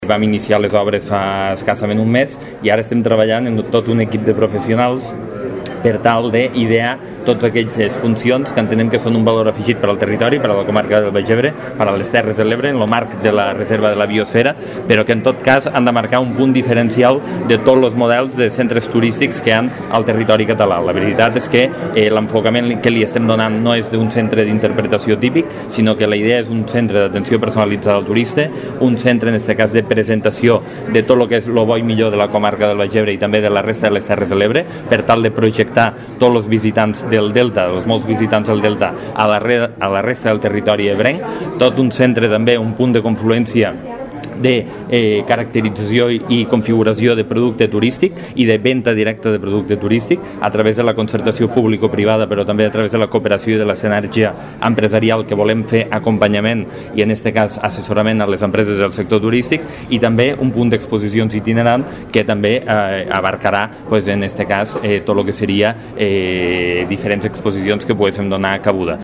L’esdeveniment ha tingut lloc a la Cambra de Comerç de Tortosa i ha comptat amb la participació del president del Consell Comarcal del Baix Ebre, Lluís Soler, el delegat del Govern a les Terres de l’Ebre, Xavier Pallarès, consellers de l’ens i altres representants institucionals.
(tall de veu) Lluís Soler explica la rellevància del CIDET